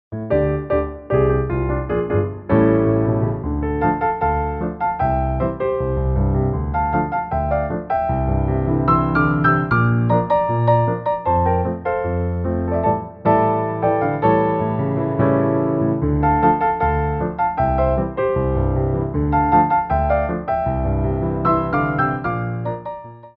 Medium Allegro 2
4/4 (8x8)